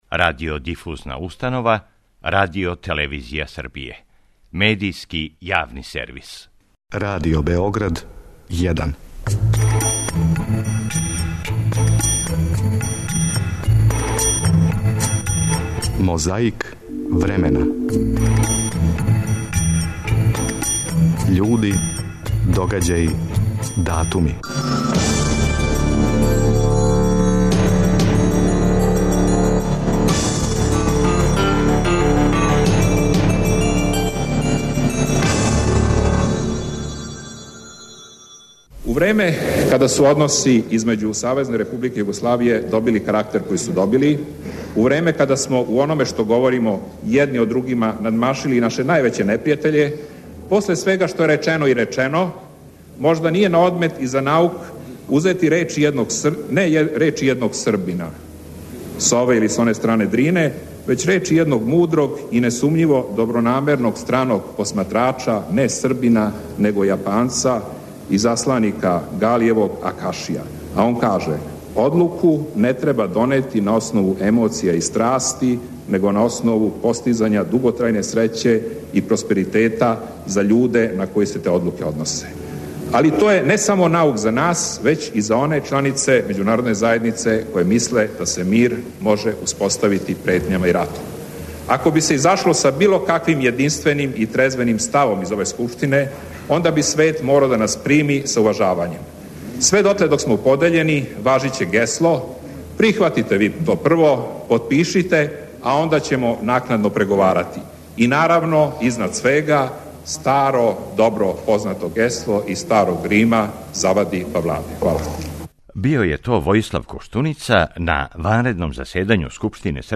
У "Мозаику времена" слушамо делове излагања неких говорника. И генерал Ратко Младић се налази у овом издању емисије, захваљујући тонском архиву Радио Београда.